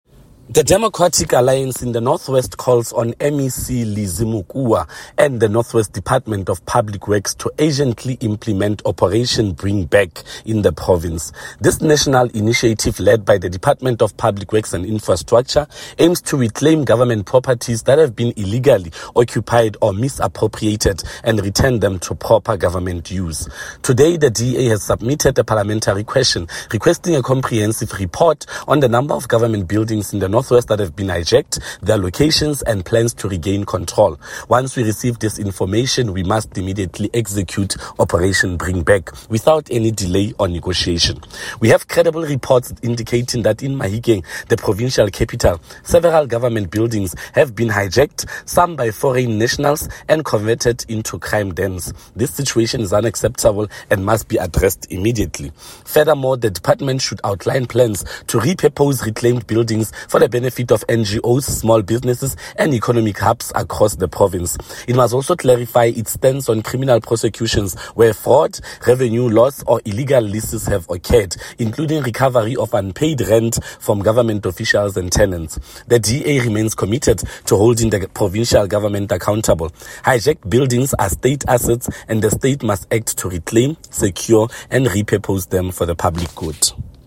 Note to Broadcasters: Please find linked soundbite in
English by Freddy Sonakile MPL.